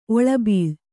♪ oḷabīḷ